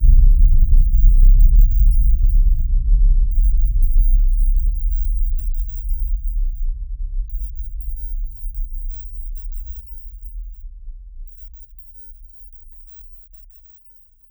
nuclear_detonation_far_away.ogg